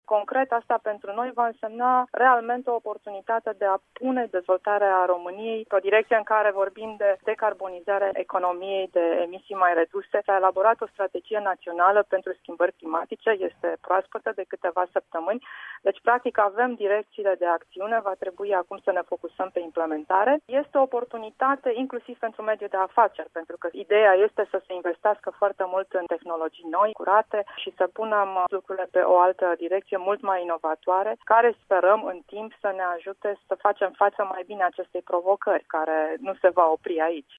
Ministrul român al Mediului, Cristina Paşca a fost desemnată reprezentantul Uniunii în discuţiile privind adaptarea la schimbările climatice, un subiect important pentru marea majoritate a ţărilor și o reuşită şi pentru România, a declarat azi pentru RRA, Cristina Paşca: